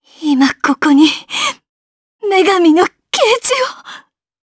Isaiah-Vox_Skill7_jp_c.wav